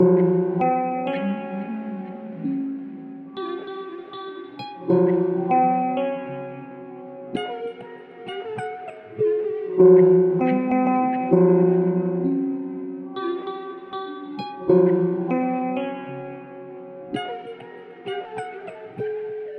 描述：调：失谐的Fmaj 速度：98bpm Harmor实验。
Tag: 98 bpm Grime Loops Piano Loops 3.30 MB wav Key : F